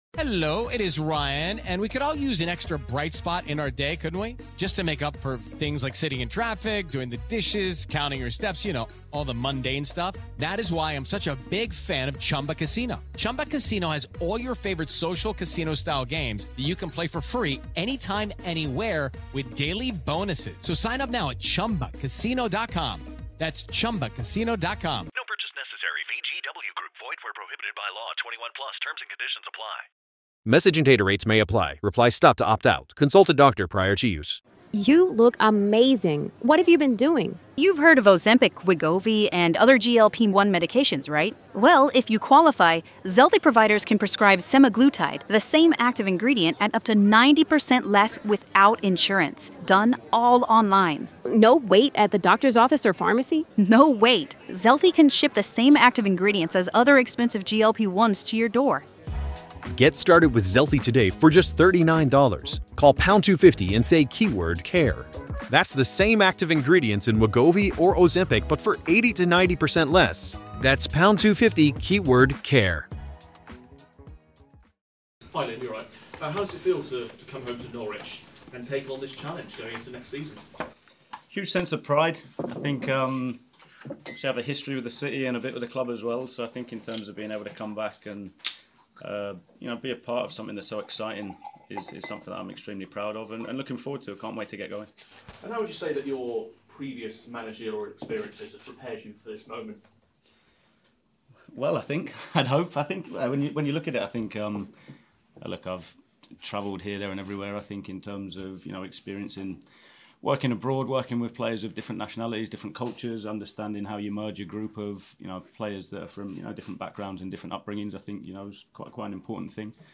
Liam Manning first Norwich City press conference